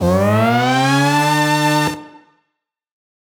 Index of /musicradar/future-rave-samples/Siren-Horn Type Hits/Ramp Up
FR_SirHornB[up]-E.wav